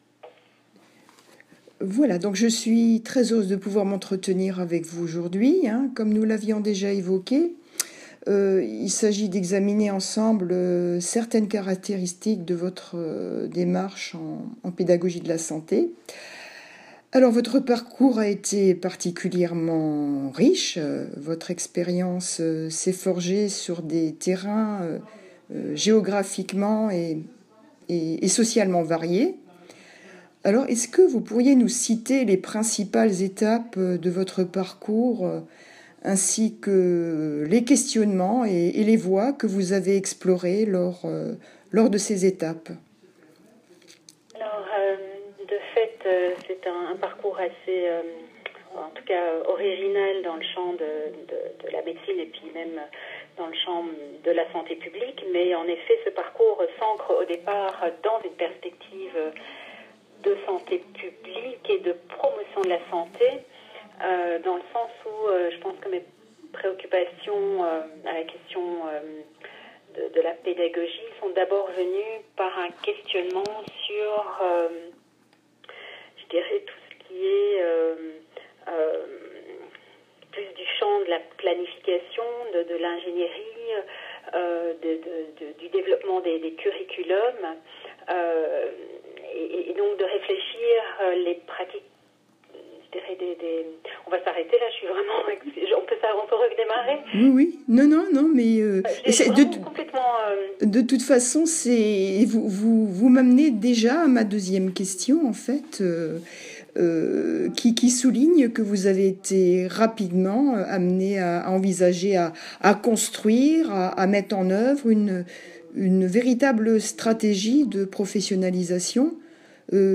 Ingénierie de la professionnalisation dans les organisations de santé (IPOS) Interview